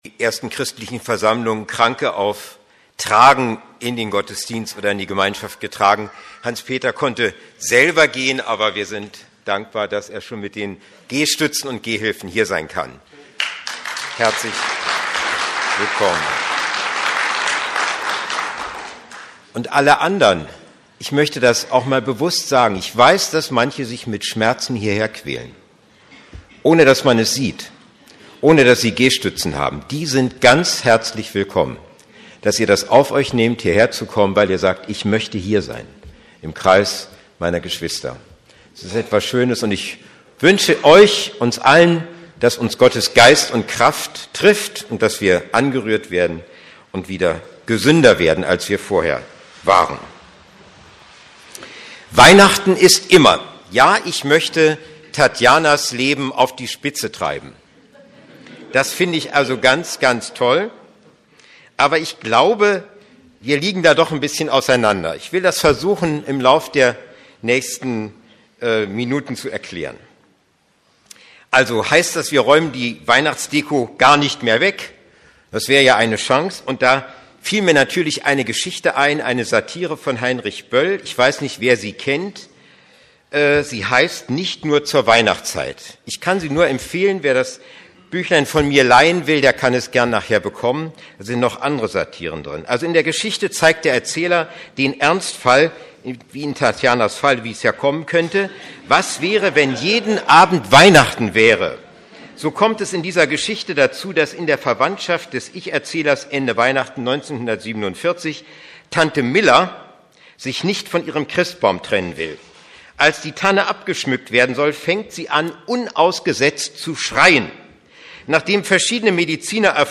Weihnachten ist immer ~ Predigten der LUKAS GEMEINDE Podcast